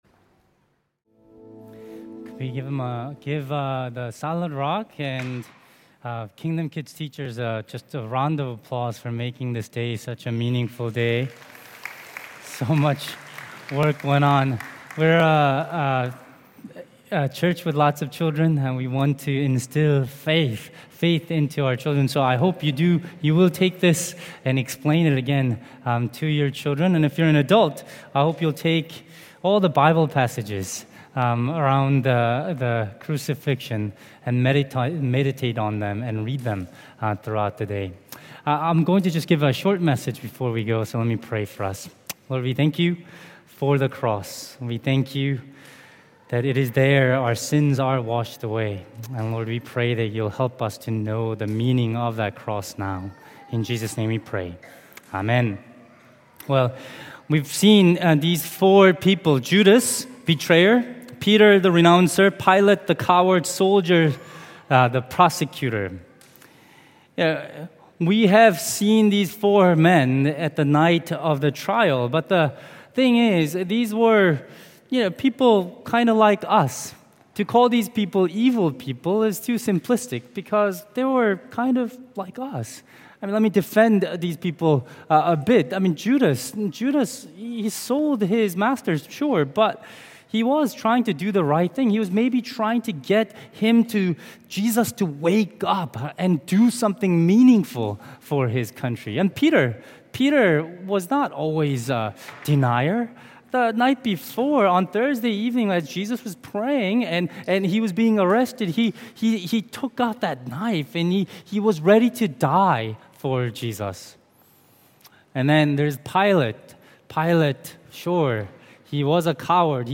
Good Friday Service 2025 – Shatin Anglican Church
Sermons